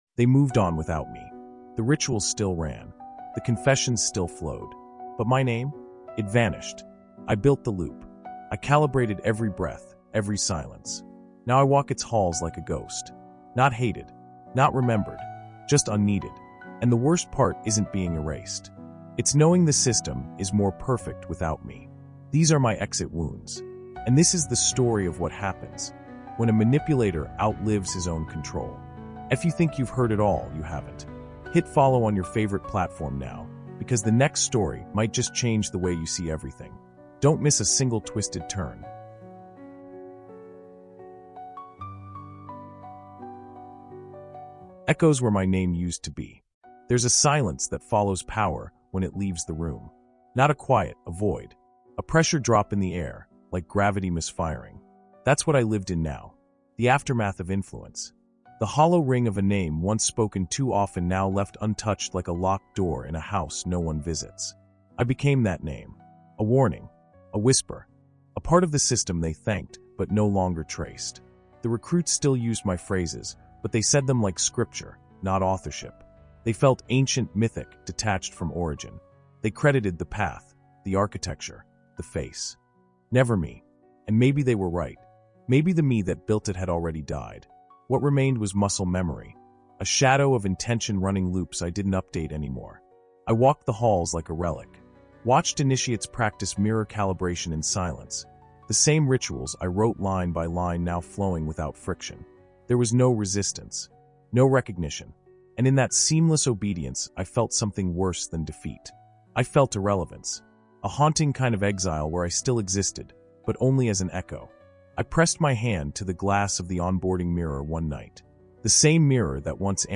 Through a ghostlike pace and emotionally immersive narration, listeners are drawn into a world of power, memory, and trauma.